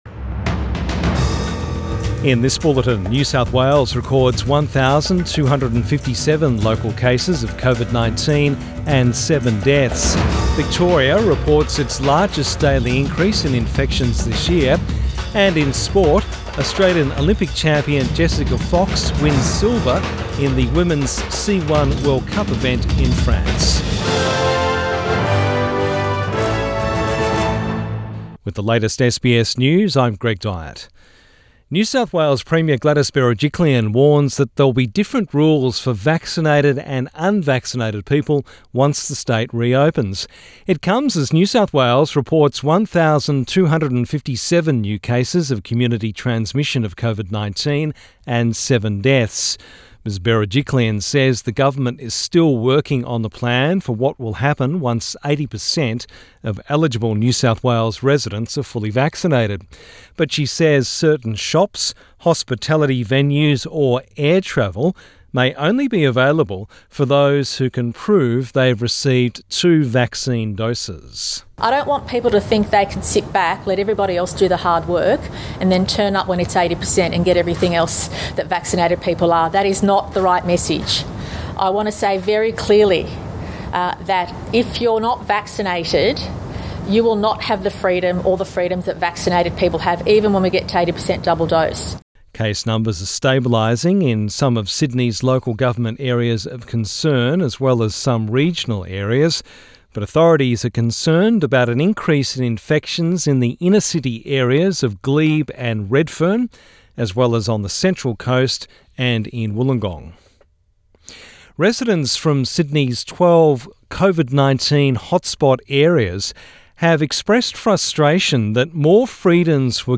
PM bulletin 13 September 2021